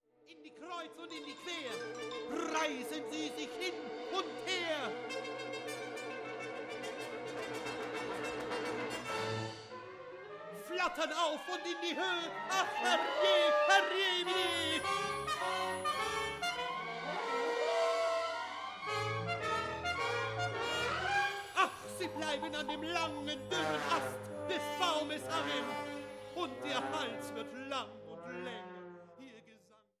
Konzerte für Sprecher und Orchester